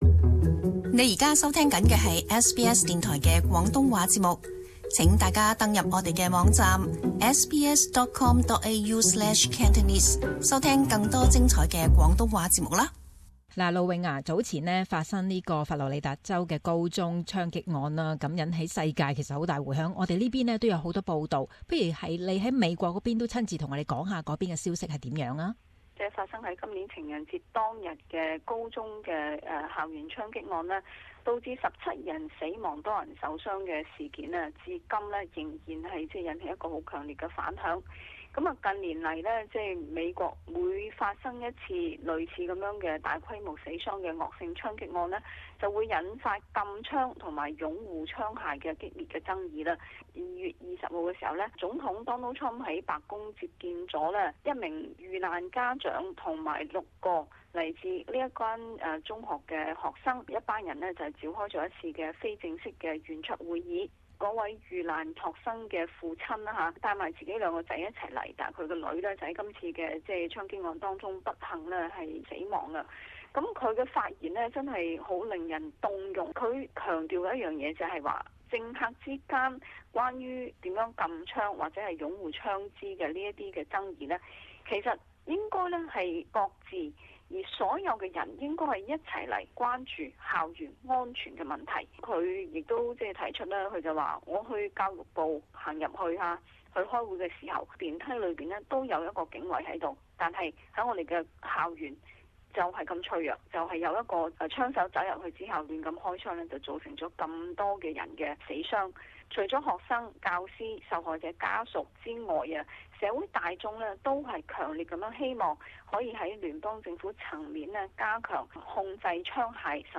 【美國通訊】校園槍擊案無法促使全面禁槍